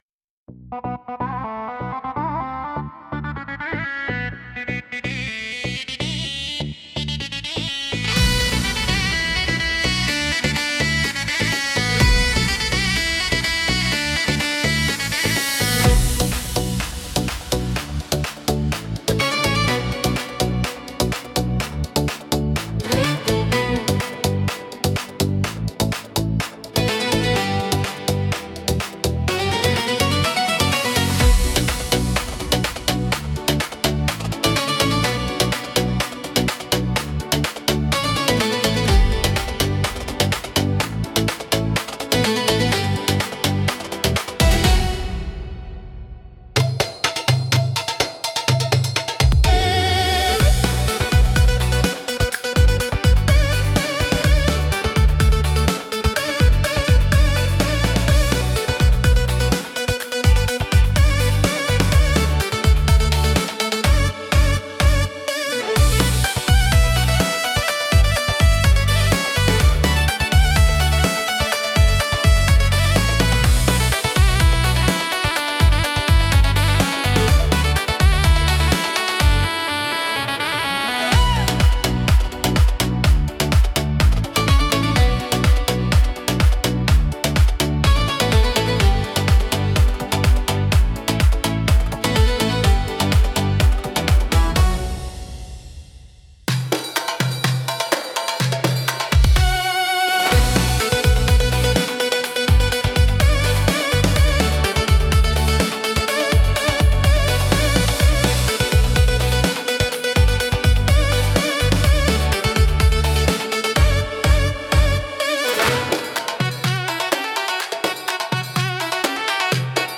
独特のメロディとリズムで聴く人の感覚を刺激します。